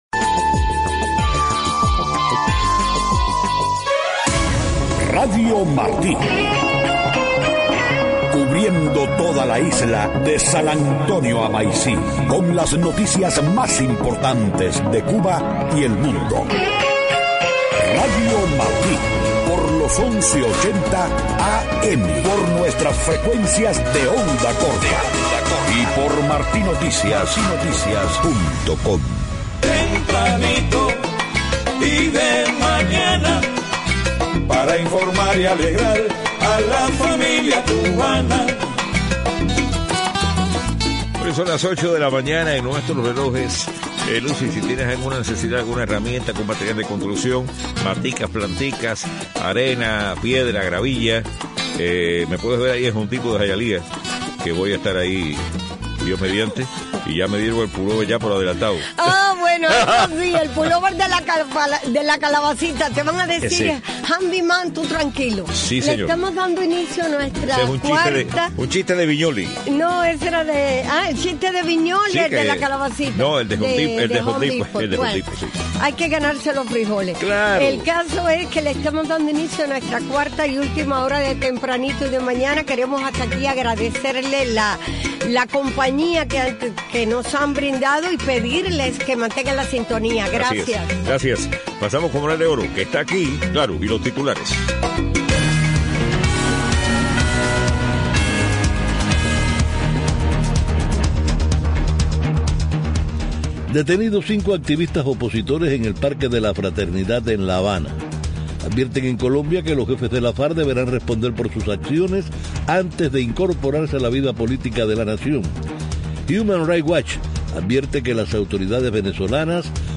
8:00 a.m. Noticias: Detenidos cinco activistas opositores en el Parque de la Fraternidad en La Habana. Advierten en Colombia que las FARC deberán responder por sus acciones antes de incorporarse a la vida política de la nación. HRW advierte que el presidente Maduro y sus aliados están usando el sistema de justicia para castigar a sus críticos.